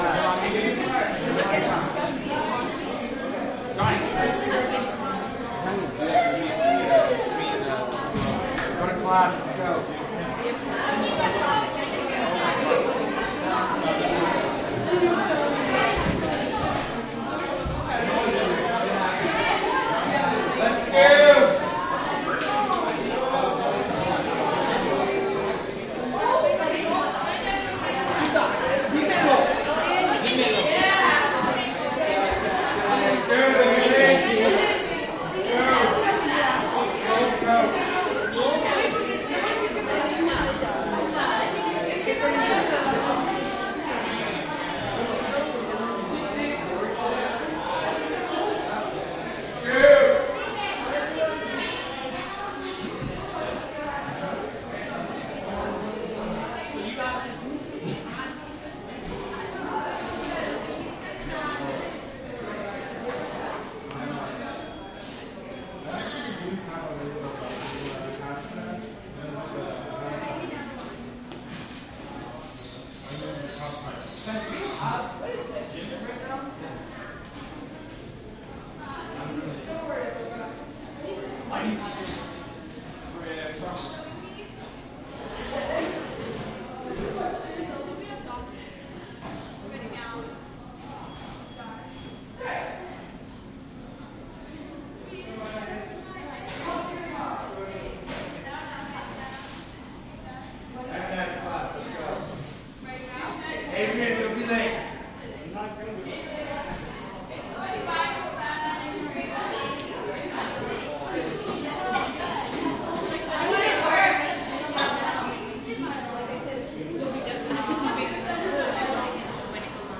蒙特利尔 " 人群中繁忙的餐厅roiduplateau 蒙特利尔，加拿大
Tag: 蒙特利尔 INT 餐厅 人群 加拿大